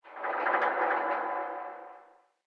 ambienturban_27.ogg